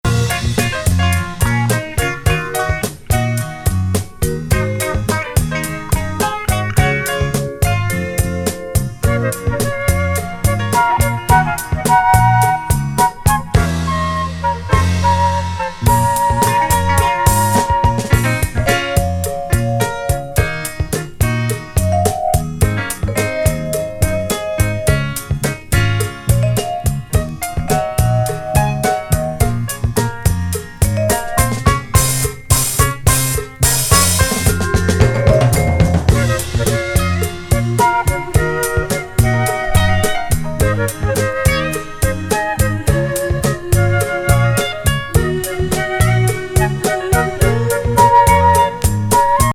国内オンリー・テナー・サックス・インスト。